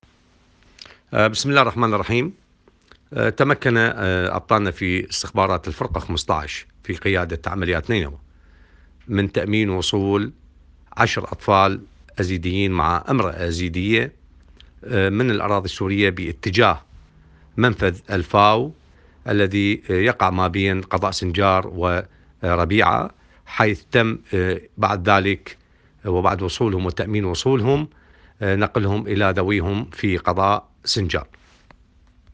الناطق الرسمي لخلية الاعلام الامني العميد يحيى رسول يعلن عن تأمين وصول 10 اطفال ايزيدين مع امرأة من الاراضي السورية الى ذويهم في سنجار .